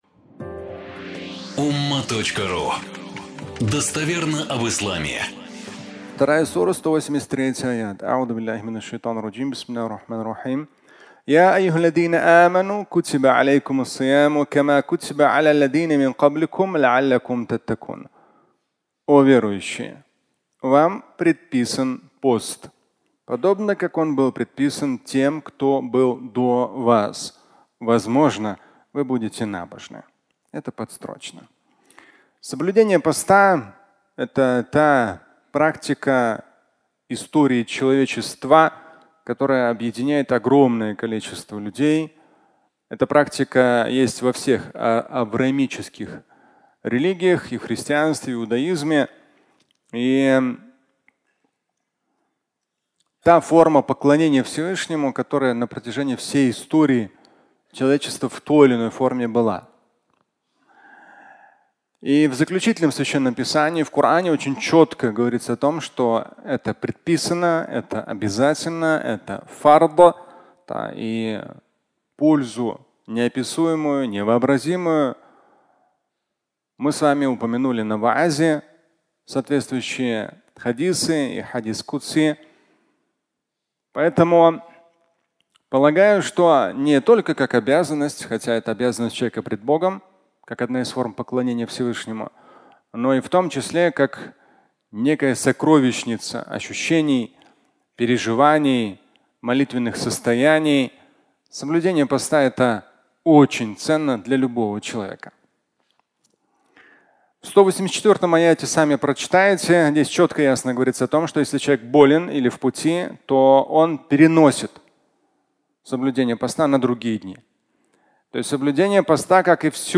Фрагмент пятничной лекции
Пятничная проповедь